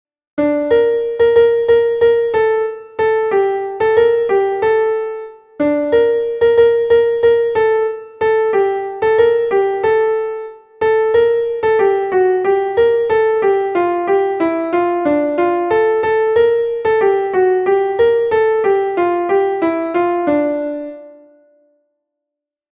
Dantzakoa
Lauko txikia (hg) / Bi puntuko txikia (ip)
AABB